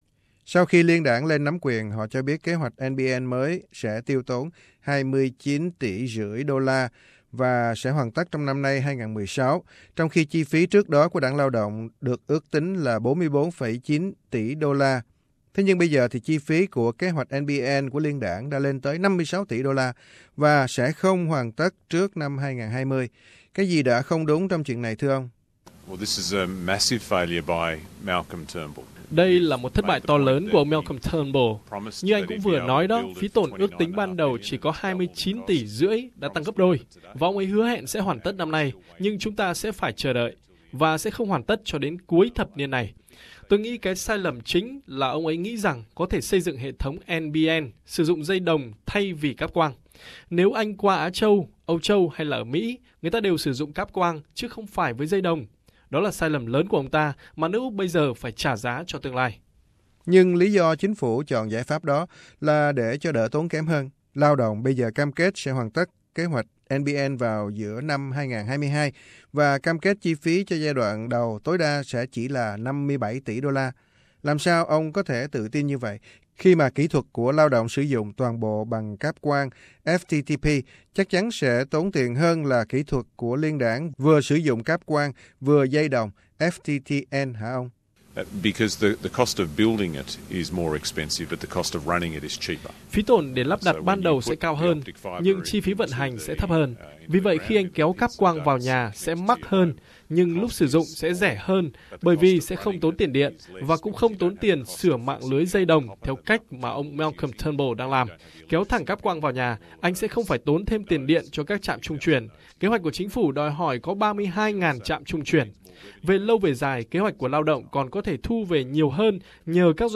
Bầu cử 2016: Phỏng vấn Jason Clare MP